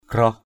/ɡ͡ɣrɔh/ (đg.) sủa = aboyer. malam asau graoh hemhem ml’ as~@ _g<H h#h# chó sủa trong đêm nghe rờn rợn.